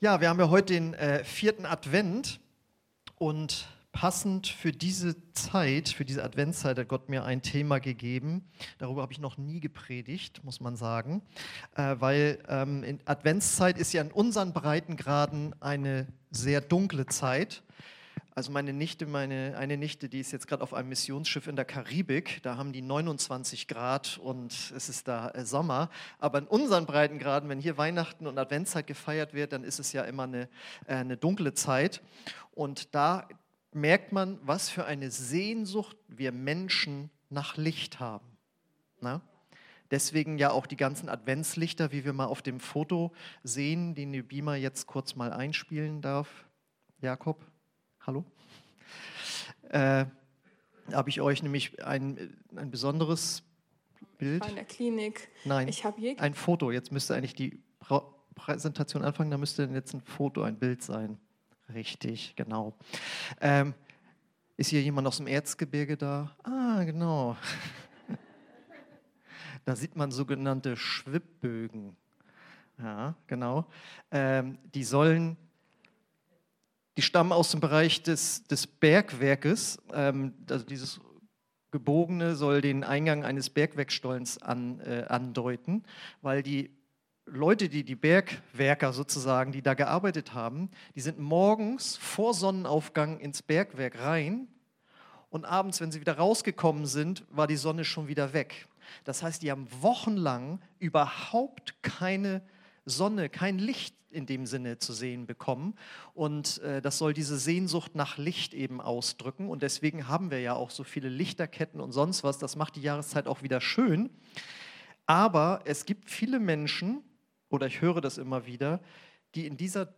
Predigten (v1) – OASIS Kirche